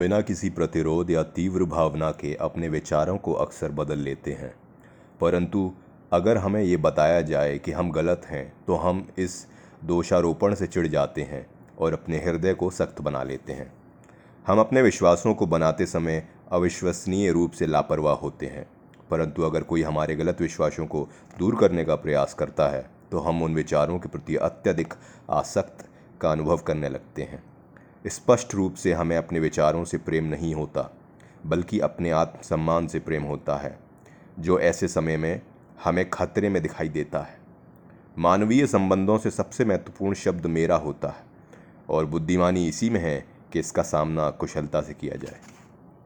电台主播【腔调魅力】